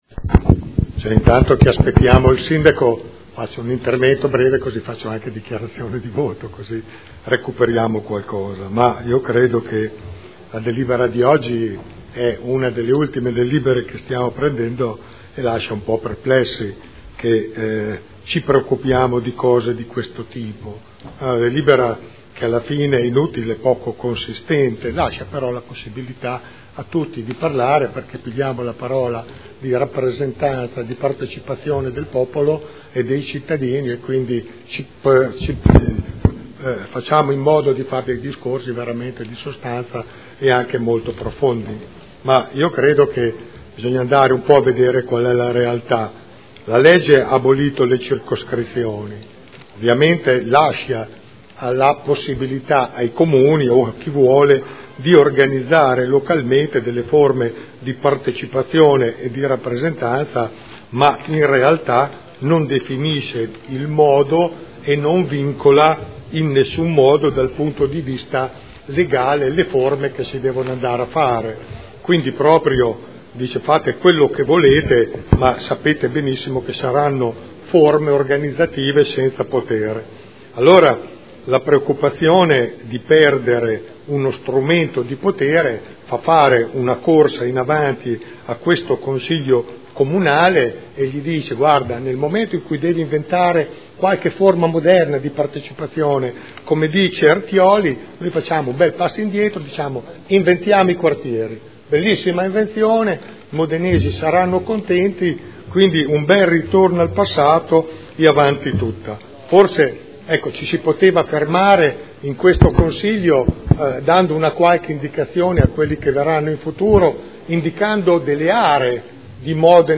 Gian Carlo Pellacani — Sito Audio Consiglio Comunale
Seduta del 3 aprile. Proposta di deliberazione: Regolamento di prima attuazione della partecipazione territoriale – Approvazione.